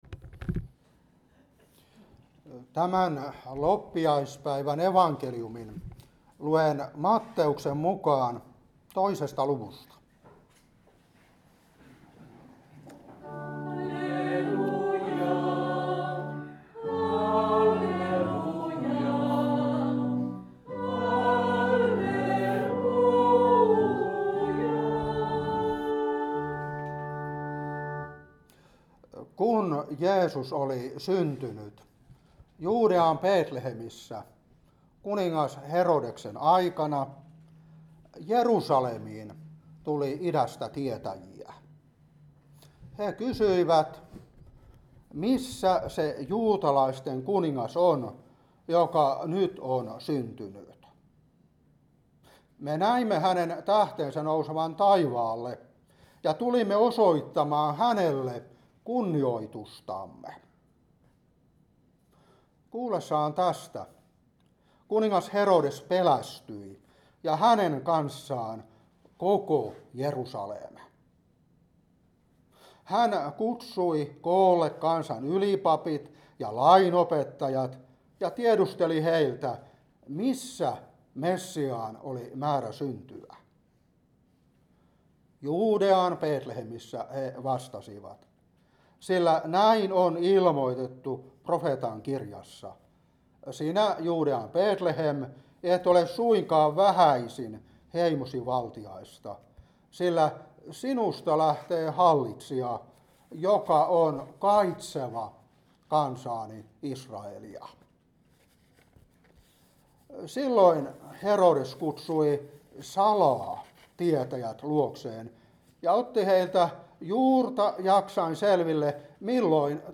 Saarna 2026-1.